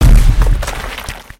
rock_hit2.wav